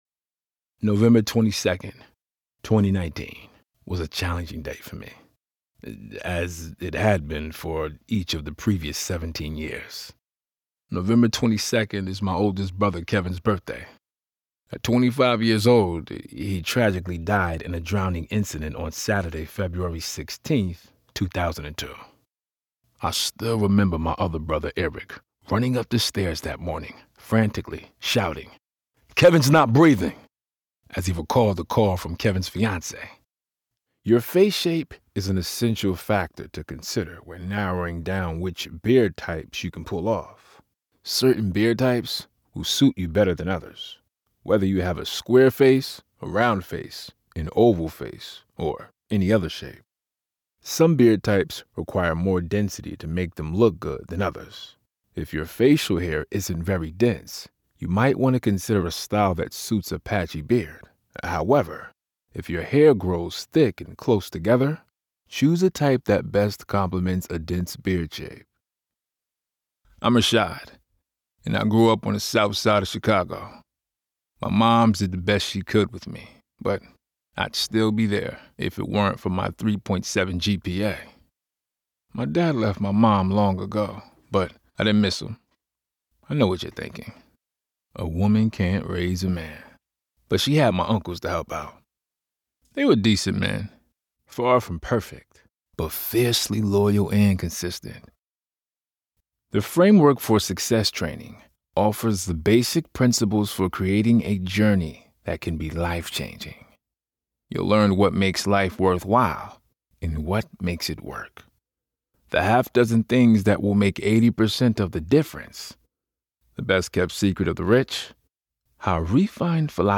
Full-time VO talent offering a home studio and quick project turnaround.
AUDIOBOOK DEMO
Young Adult
Middle Aged
AUDIOBOOK-DEMO.mp3